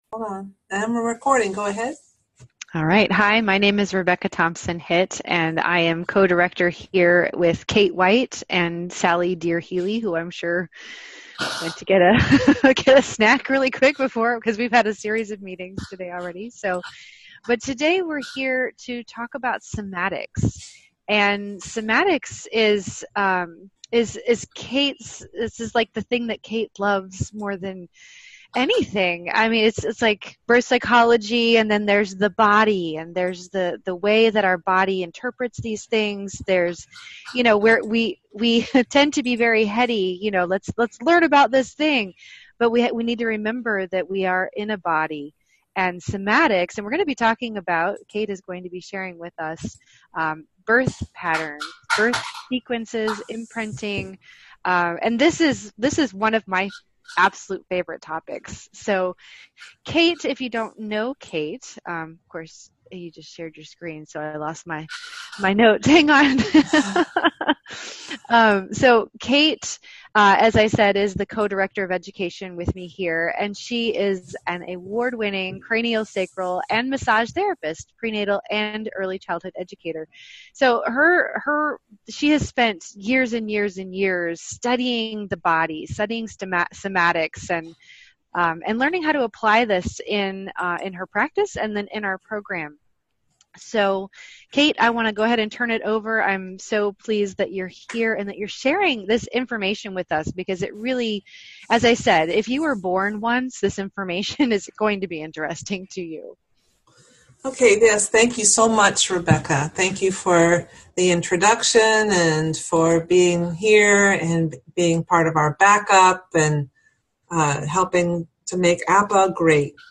Lectures with some of the brightest in the PPN field to deepen your understanding of the application of Pre and Perinatal Psychology